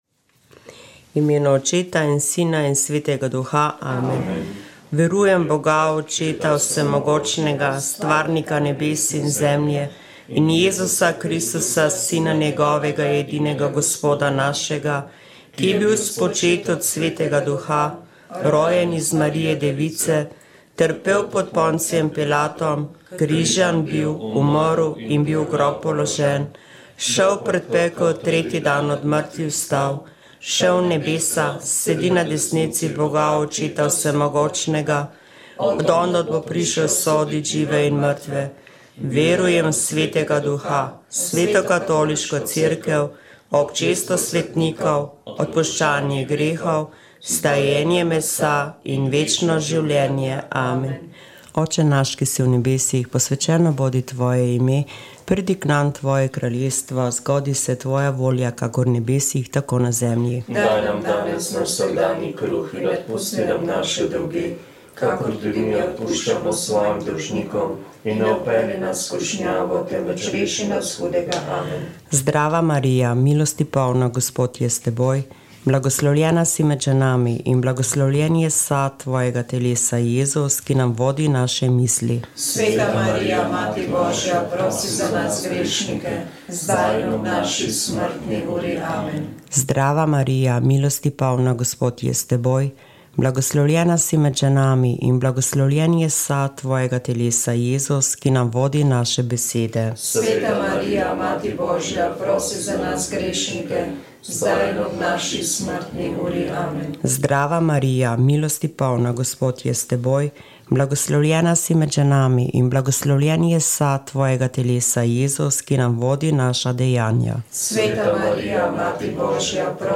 Molili so člani Karitas iz župnije Domžale.